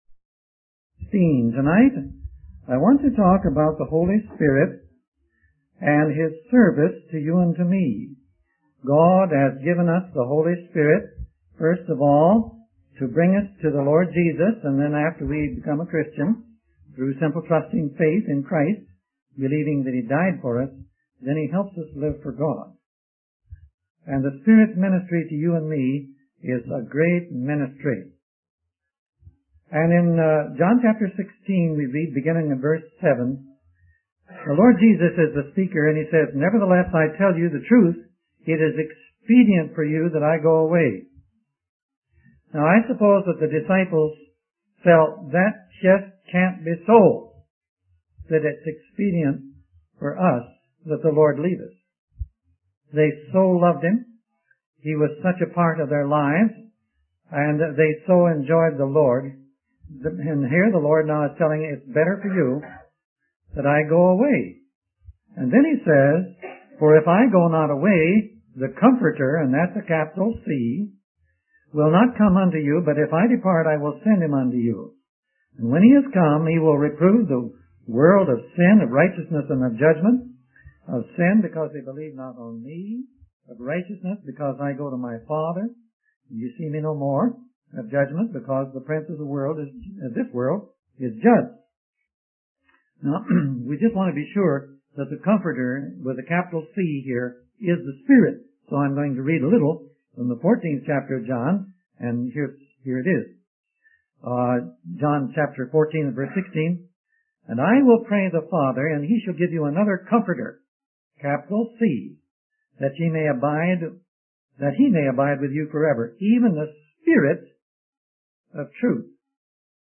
In this sermon, the speaker emphasizes the importance of being led by the Holy Spirit. They explain that the Spirit reveals the greatest truths of the Bible directly to believers.